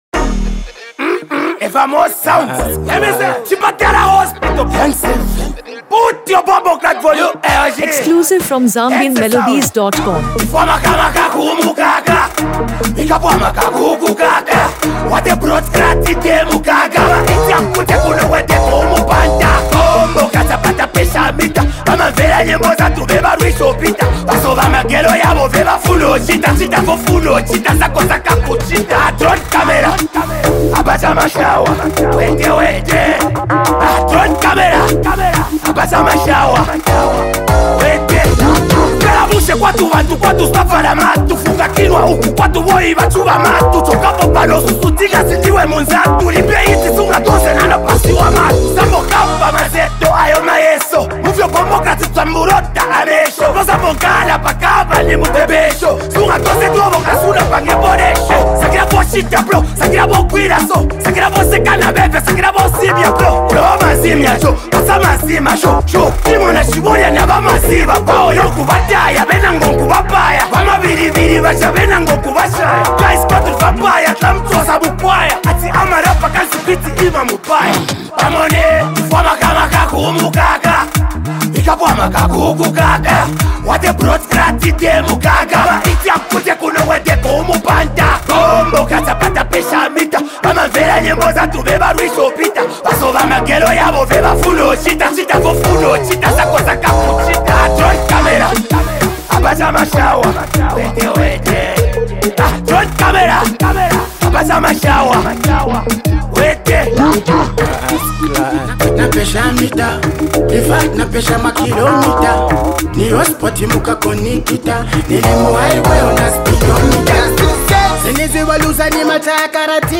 Bold Afro‑Dancehall Vibes
a dynamic and attention-grabbing Zambian track